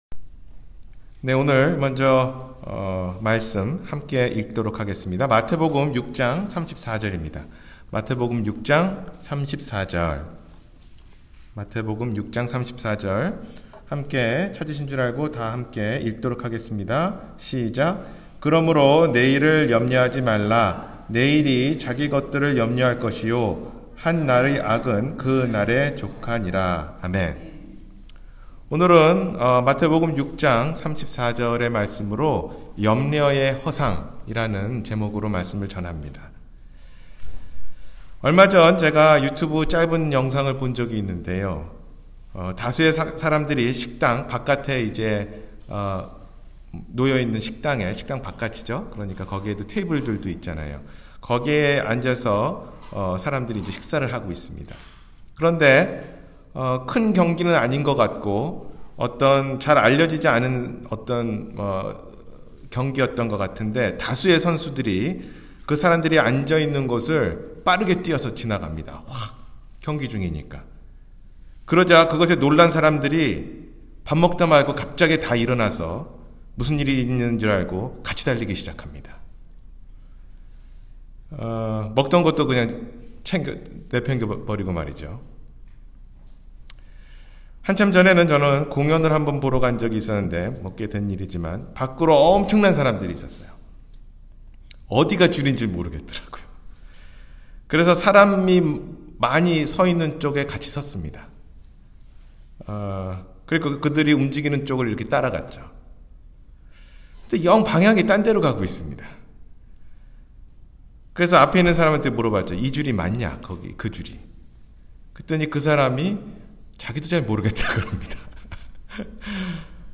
염려의 허상-주일설교